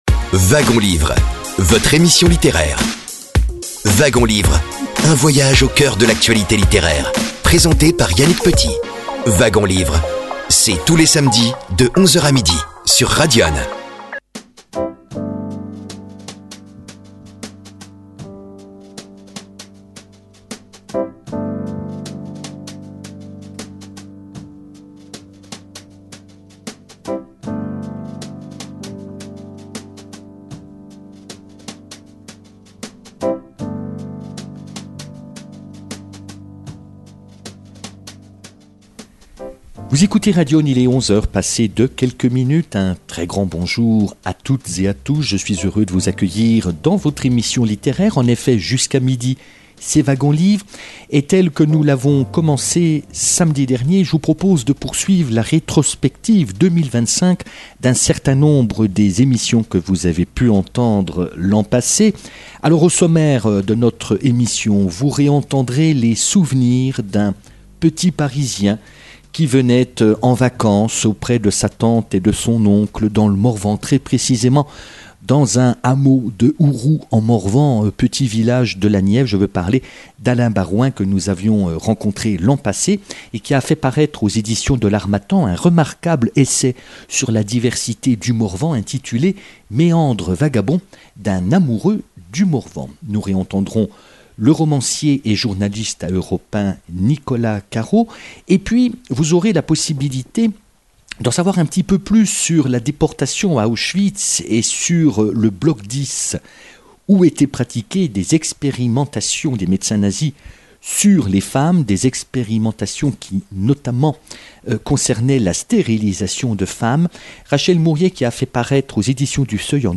Nous vous proposons des extraits d’émissions diffusées durant 2025 sur l’antenne de Radyonne FM.